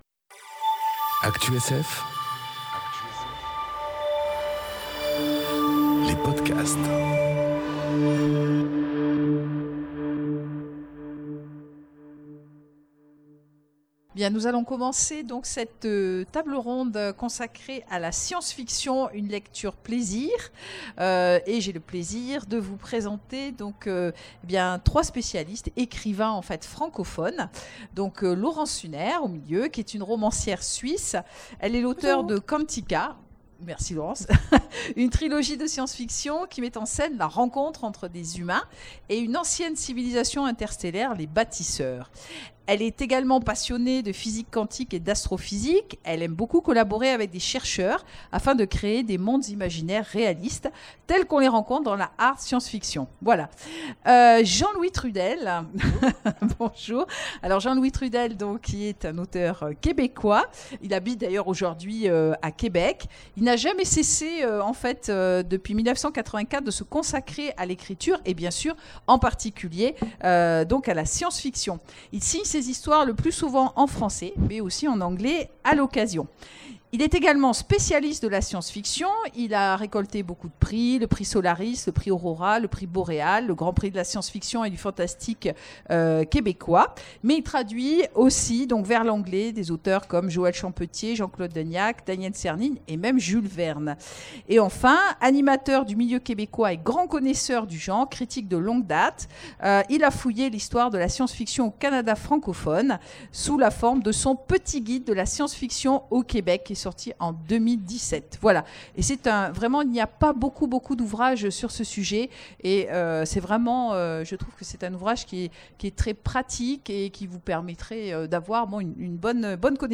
Conférence La science-fiction... Une lecture plaisir ! enregistrée aux Imaginales 2018